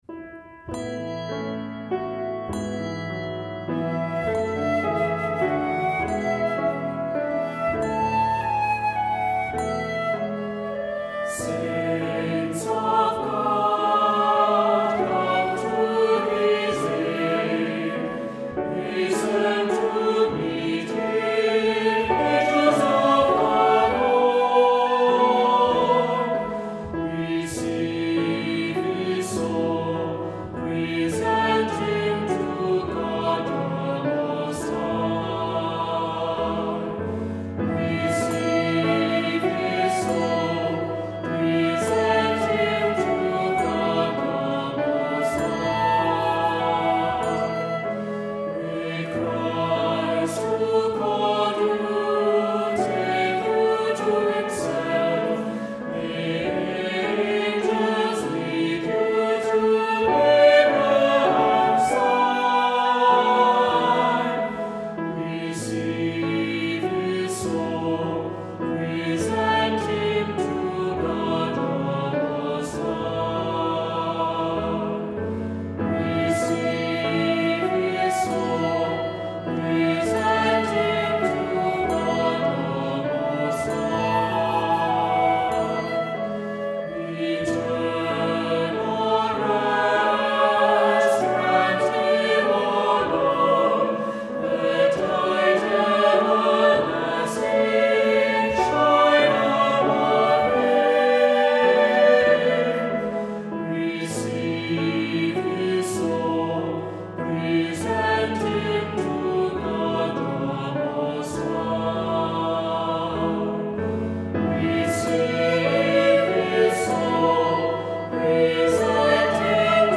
Voicing: Unison with descant; Cantor; Assembly